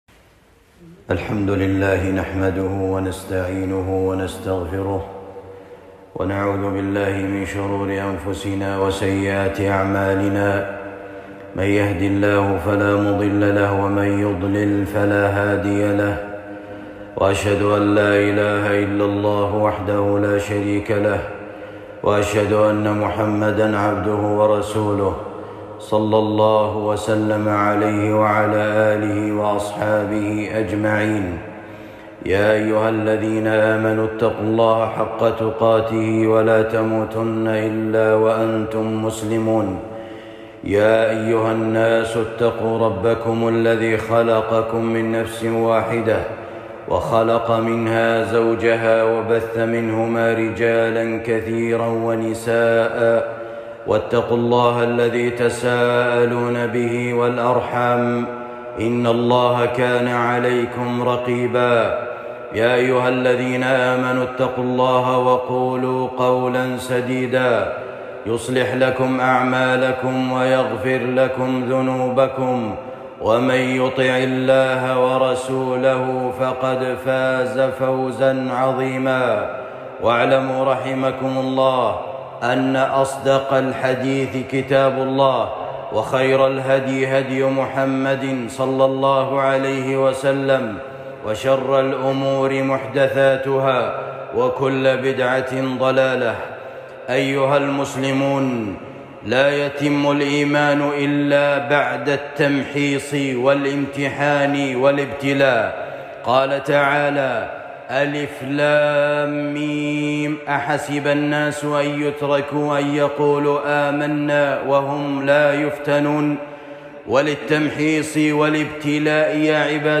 فوائد التمحيص خطبة جمعة